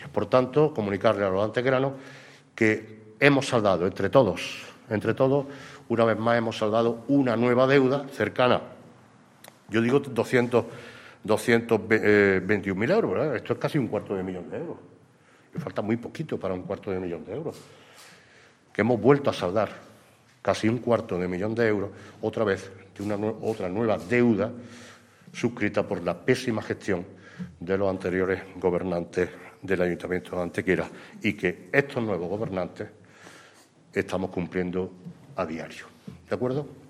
El alcalde de Antequera, Manolo Barón, el teniente de alcalde delegado de Contratación, Juan Rosas, el teniente de alcalde de Tráfico, Ángel González, y el teniente de alcalde de Hacienda, Antonio García, han comparecido hoy en rueda de prensa para informar del pago de 221.948,77 euros que el Ayuntamiento de Antequera ha efectuado con fecha de 7 de enero para hacer así frente a la sentencia del Juzgado de lo Contencioso y Administrativo de Málaga en relación al contencioso interpuesto por la antigua empresa concesionaria del transporte urbano de nuestra ciudad, Protomed 10, respecto a incumplimientos de la relación contractual establecida con nuestro Consistorio y que se remonta al año 2005, hace 17 años, cuando aún gobernaba el PSOE.
Cortes de voz